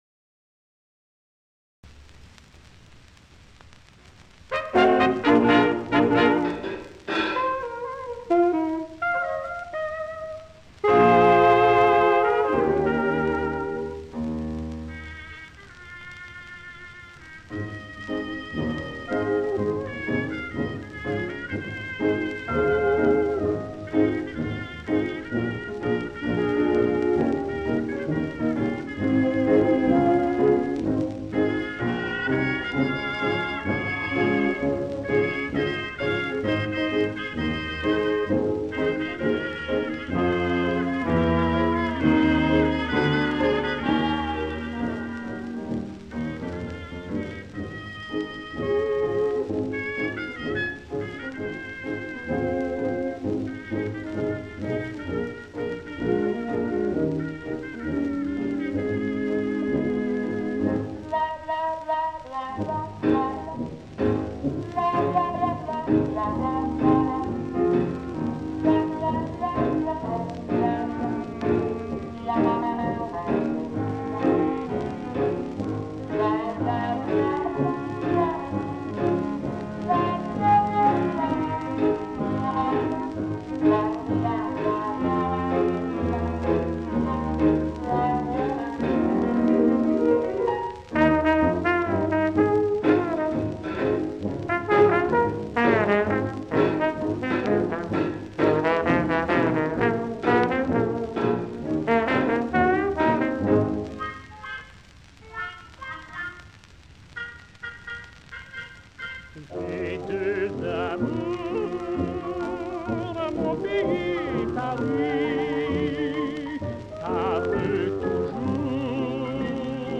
version jazzy
chanteur inconnu